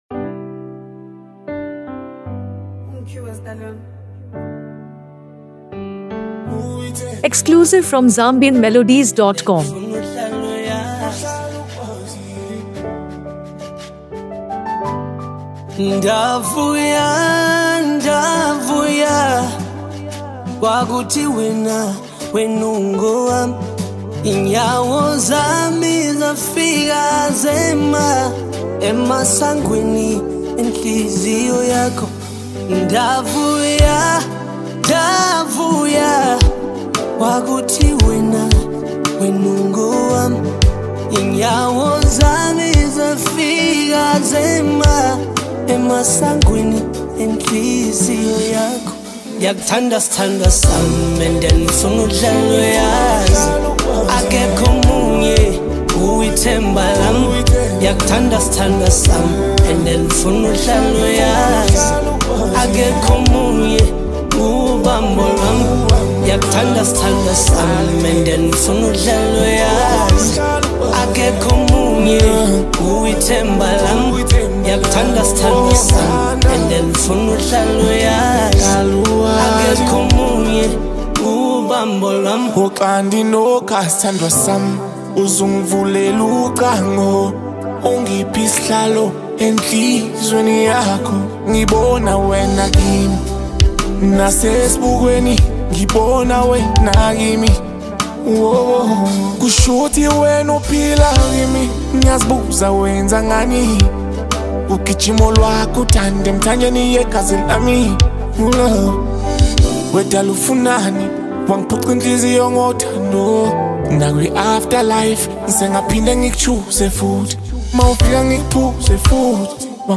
captivating Afro-soul single